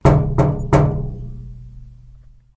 房子 " 敲铁门5